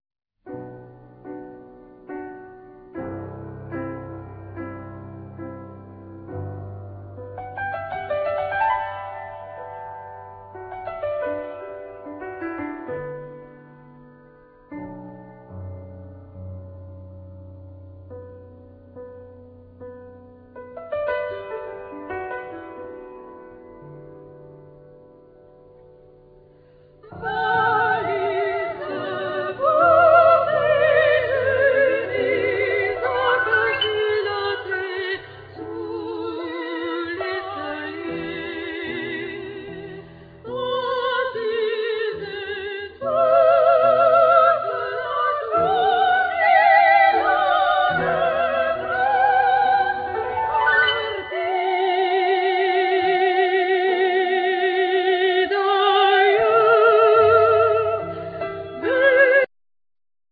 Violin
Viola
Cello
Double bass
Vocals
Flute
Clarinet
Guitars
Piano
Marimba,Vibraphone
Percussions